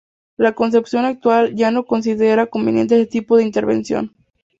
con‧cep‧ción
/konθebˈθjon/